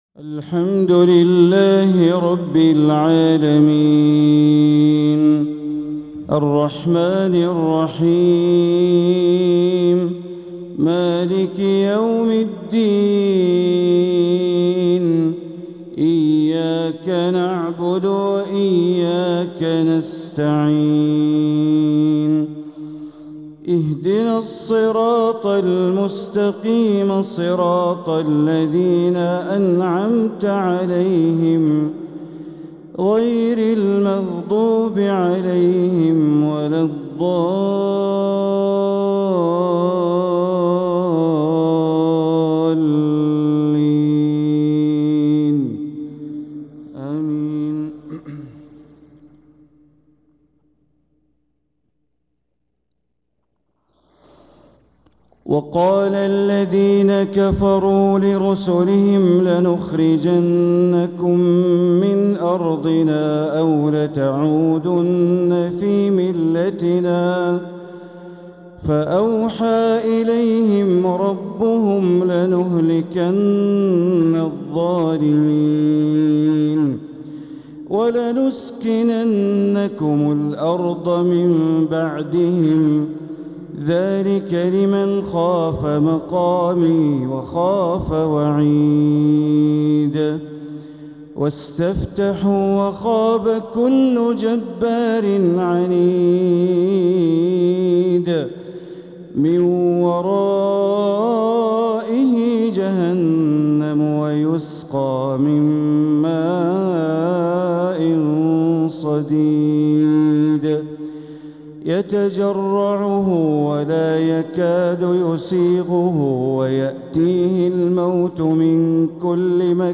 تلاوة من سورة إبراهيم ١٣-٣٠ | فجر الإثنين ١٦ ربيع الأول ١٤٤٧ > 1447هـ > الفروض - تلاوات بندر بليلة